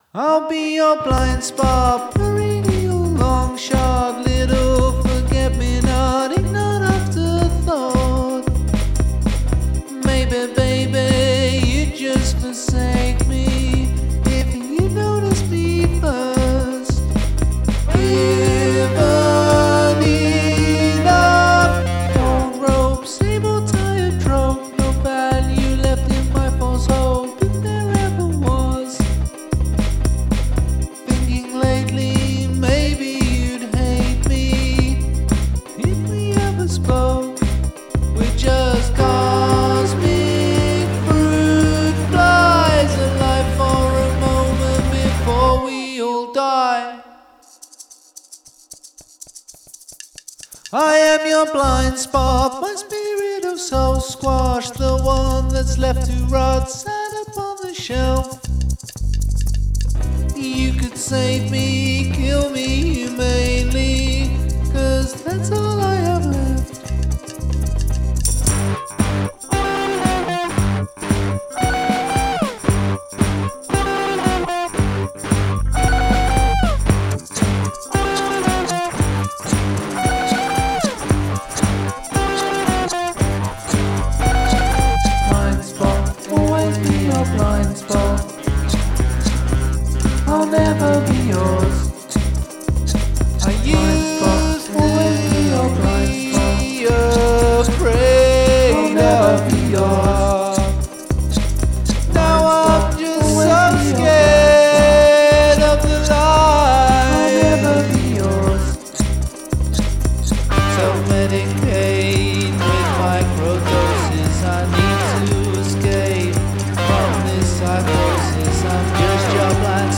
Hand percussion
And the percussion is well integrated into the song.